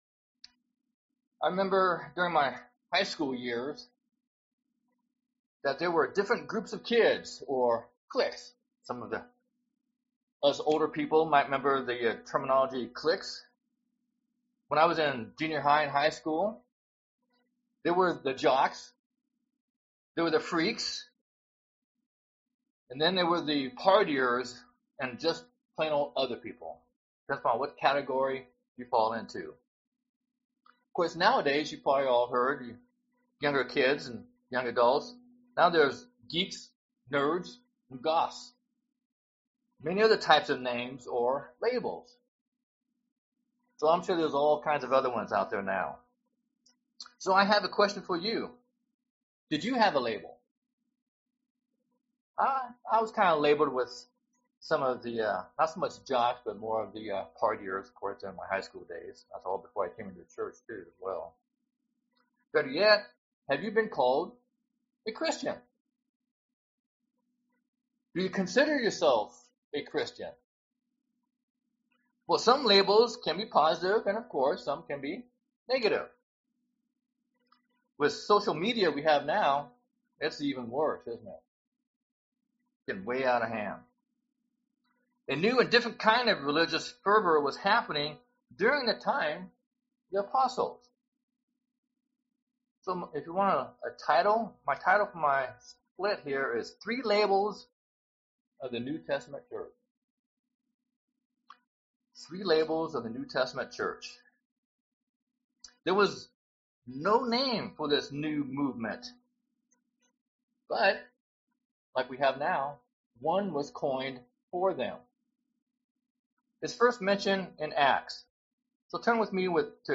They were known as Christians but given by the Romans as an insult. Listen to this split sermon to find out what they are and what they represent.
Given in Portland, OR